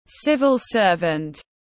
Công chức nhà nước tiếng anh gọi là civil servant, phiên âm tiếng anh đọc là /ˌsɪv.əl ˈsɜː.vənt/.
Civil servant /ˌsɪv.əl ˈsɜː.vənt/